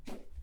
Für die unterschiedlichen benötigten Soundeffekten wurde eine eigene Soundeffekt-Library erstellt.
Woosh Cut
woosh-cut.wav